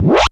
POWER UP.wav